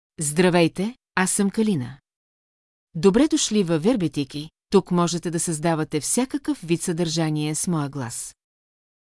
KalinaFemale Bulgarian AI voice
Kalina is a female AI voice for Bulgarian (Bulgaria).
Voice sample
Listen to Kalina's female Bulgarian voice.
Female
Kalina delivers clear pronunciation with authentic Bulgaria Bulgarian intonation, making your content sound professionally produced.